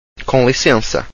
Com[ng] lissensa – lit. ‘with permission’.